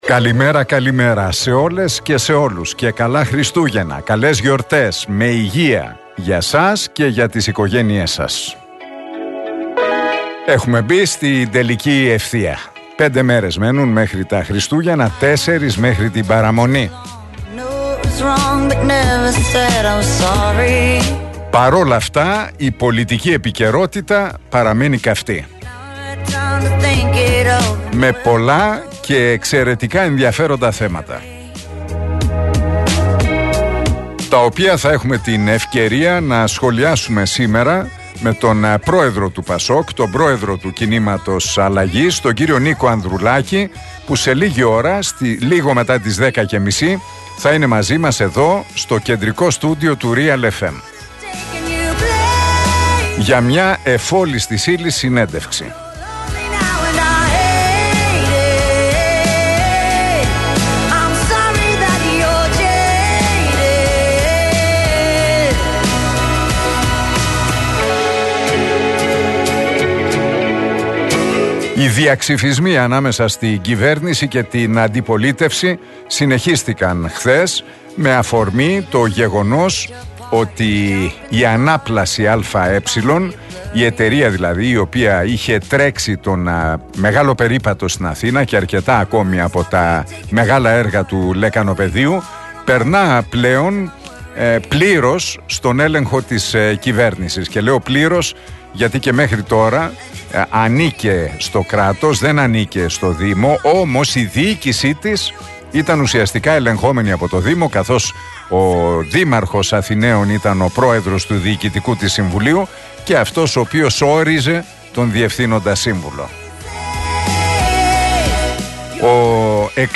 Ακούστε το σχόλιο του Νίκου Χατζηνικολάου στον RealFm 97,8, την Τετάρτη 20 Δεκεμβρίου 2023.